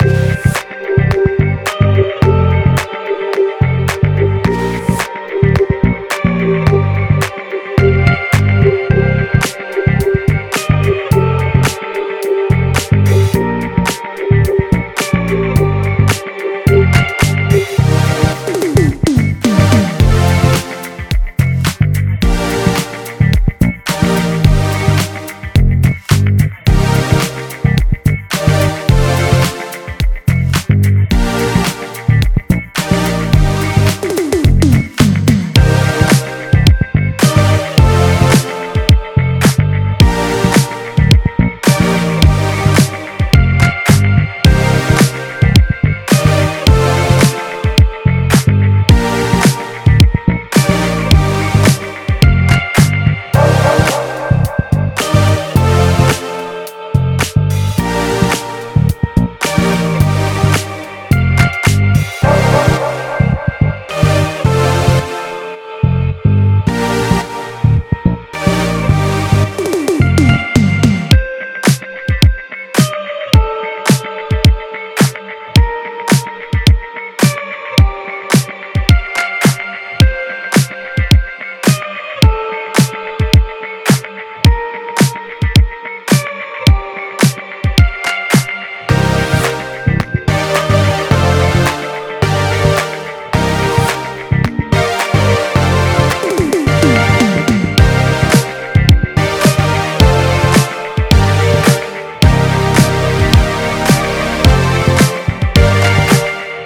רק בהתחלה התחלת עם פילטר [כותוף] פתוח וסגרת ופתחת שוב
אז השתמשתי בסאונד של האורגן עצמו עם קאטאוף מובנה שנפתח ונסגר כל הזמן